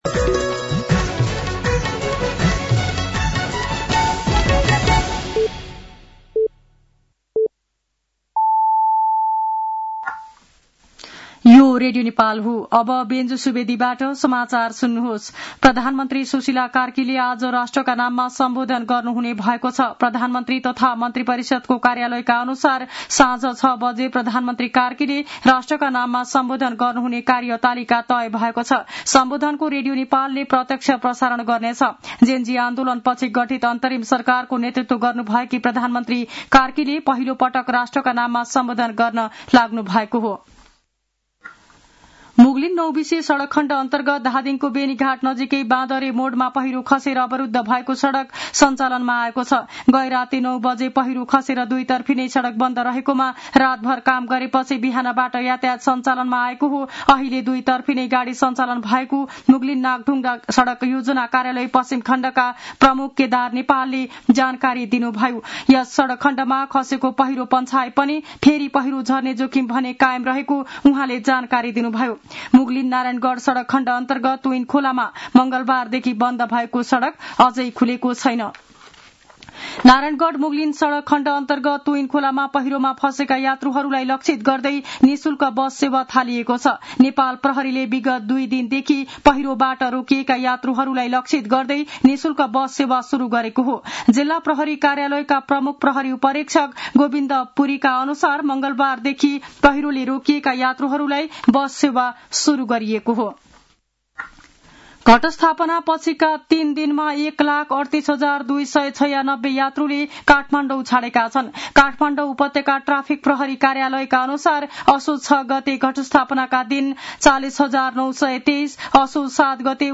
साँझ ५ बजेको नेपाली समाचार : ९ असोज , २०८२
5.-pm-nepali-news-1-7.mp3